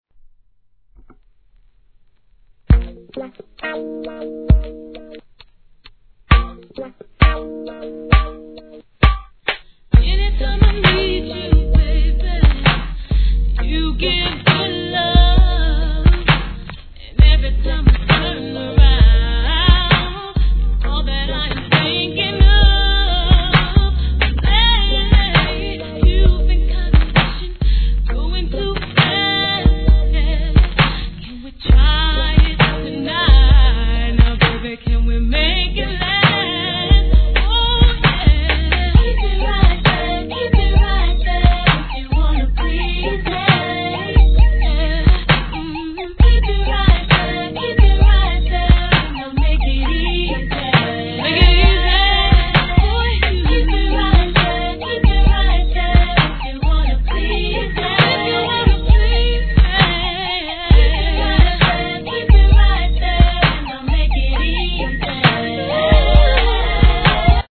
HIP HOP/R&B
しつこさを感じさせない濃厚なツイン・ソウル・ヴォーカルでじっくりと聞かせてくれるスロ〜ナンバー！